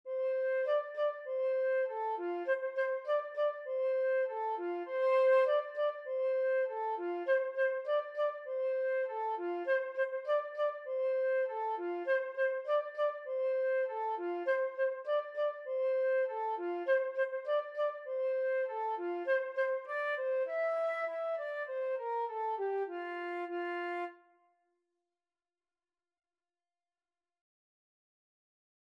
F major (Sounding Pitch) (View more F major Music for Flute )
4/4 (View more 4/4 Music)
Allegro moderato (View more music marked Allegro)
F5-E6
Flute  (View more Beginners Flute Music)
Classical (View more Classical Flute Music)